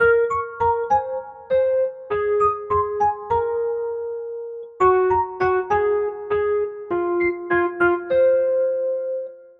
电钢琴11100bpm
描述：F小调的嘻哈/说唱钢琴循环曲。